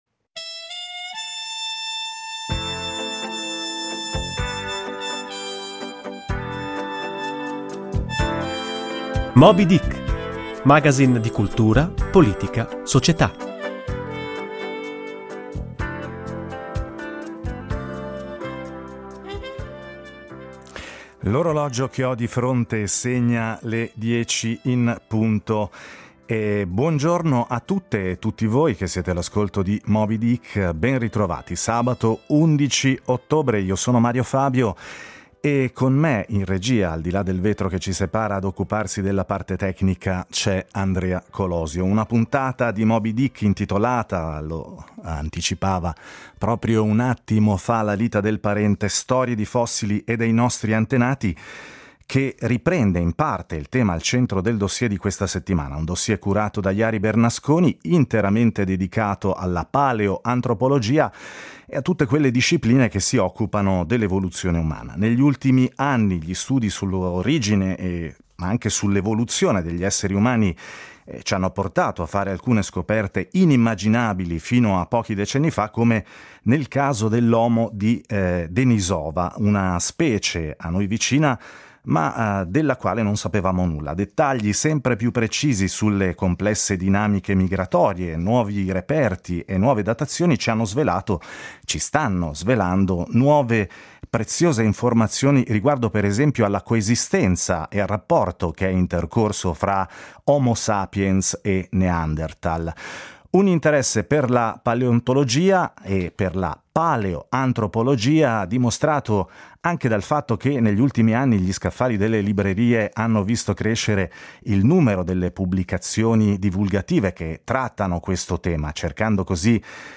Moby Dick mette in dialogo due sguardi complementari: quello del divulgatore e narratore, che restituisce la meraviglia e gli abbagli della storia, e quello dello scienziato, che ci mostra come la ricerca moderna indaghi il passato per comprendere il presente.